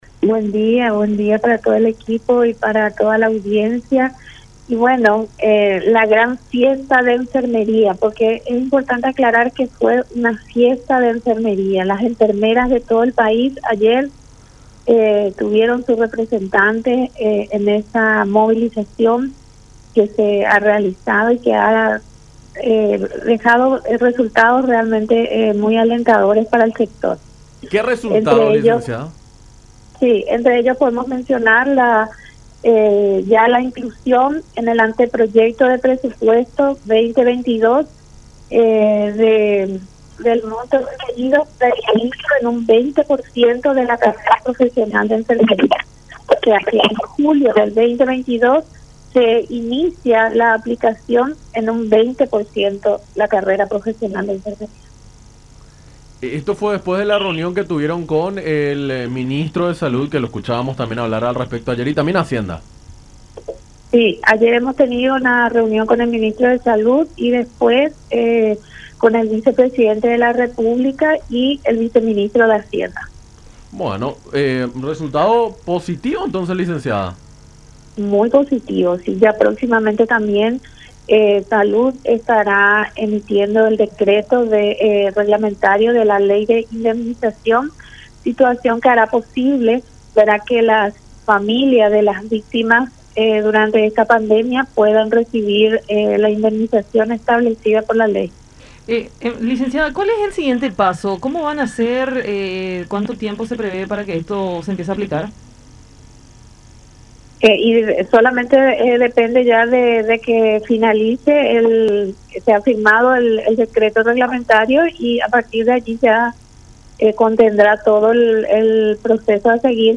en conversación con Enfoque 800 a través de La Unión